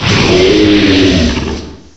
cry_not_palossand.aif